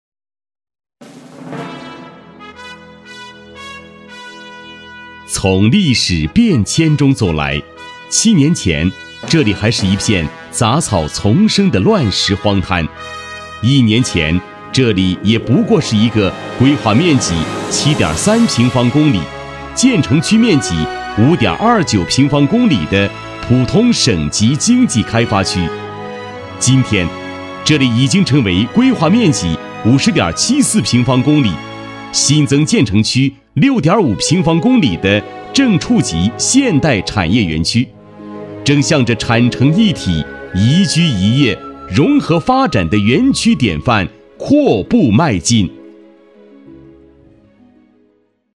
男国162_专题_党建_开发区_稳重.mp3